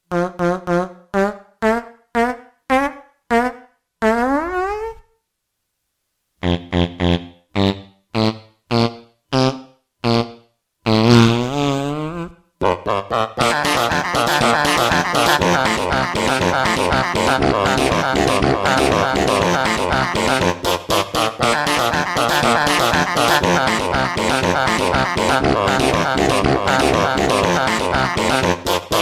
Kategorien: Lustige